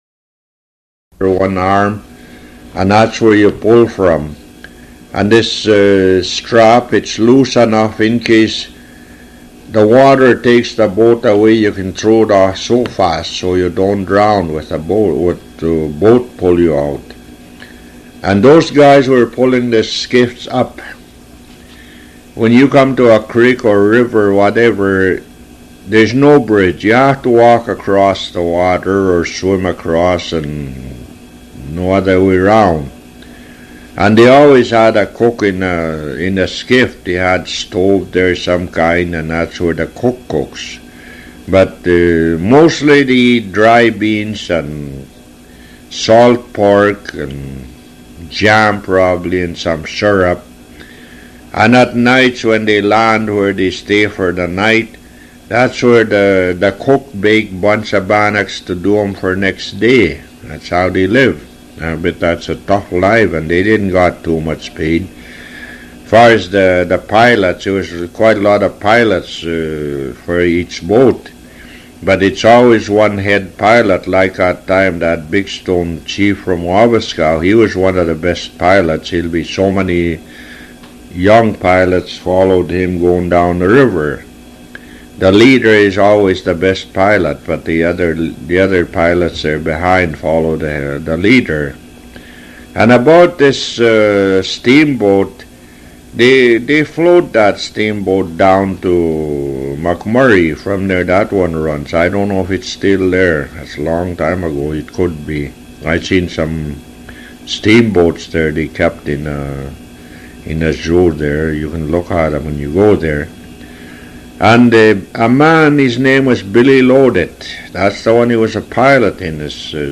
Audio Interview